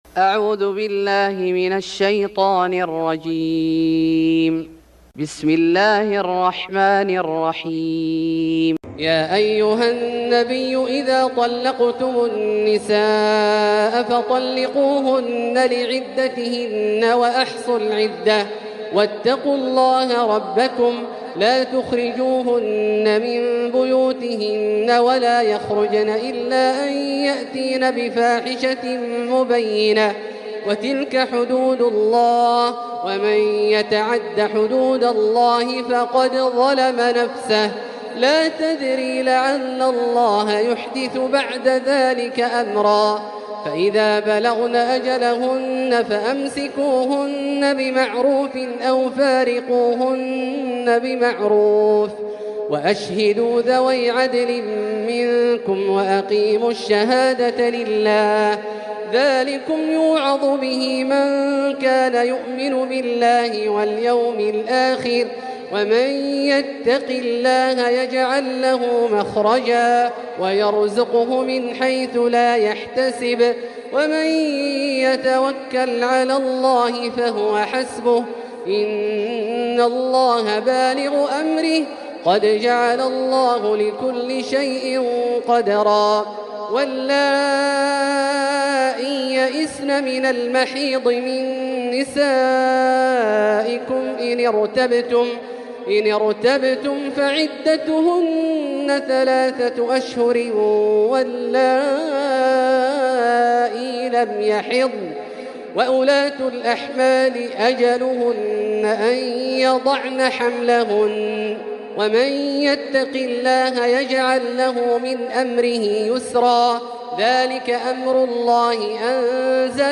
سورة الطلاق Surat At-Talaq > مصحف الشيخ عبدالله الجهني من الحرم المكي > المصحف - تلاوات الحرمين